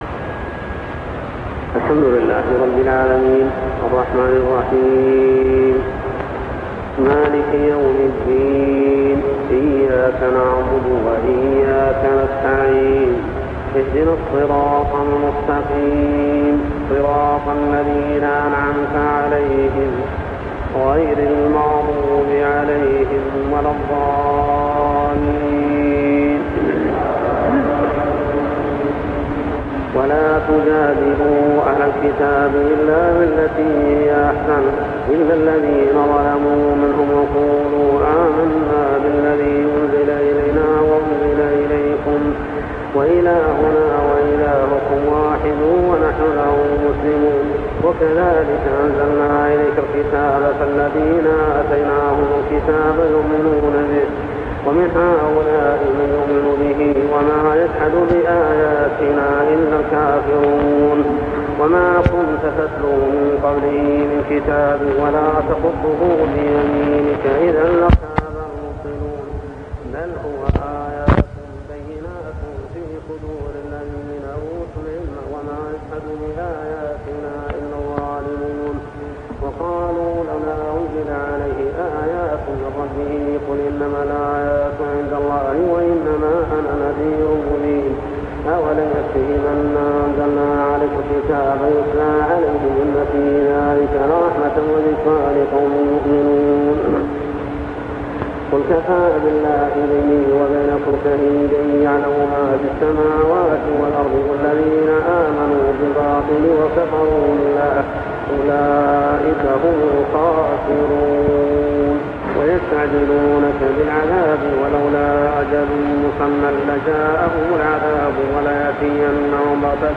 صلاة التراويح عام 1401هـ سور العنكبوت 46-69 و الروم كاملة و لقمان 1-11 | Tarawih prayer Surah Al-Ankabut, Ar-Rum, and Luqman > تراويح الحرم المكي عام 1401 🕋 > التراويح - تلاوات الحرمين